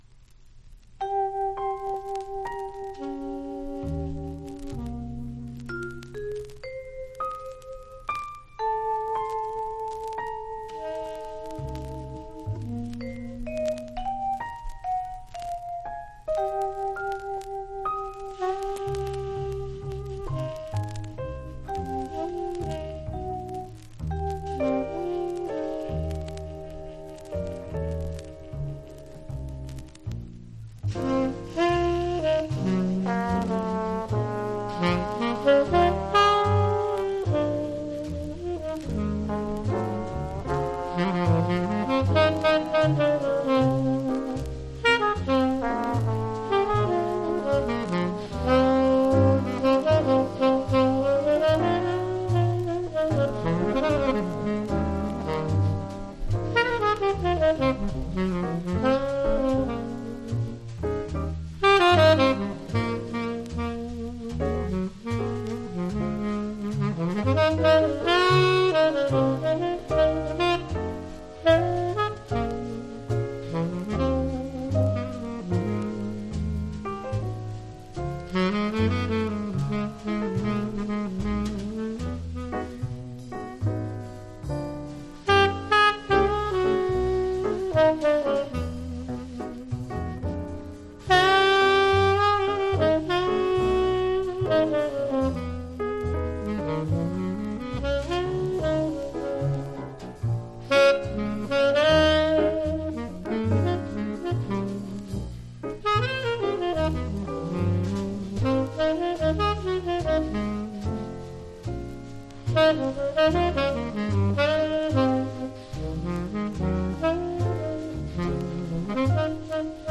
5曲Big Band。“